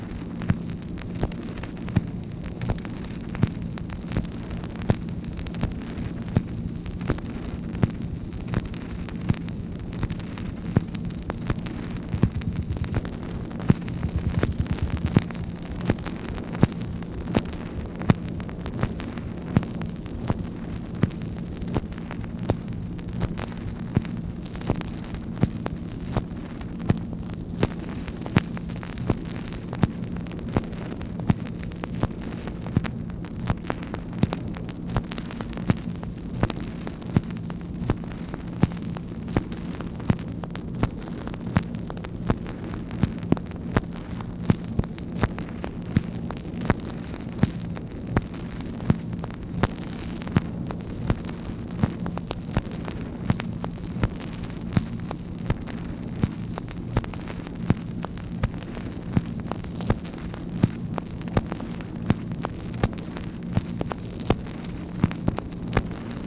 MACHINE NOISE, April 7, 1964
Secret White House Tapes | Lyndon B. Johnson Presidency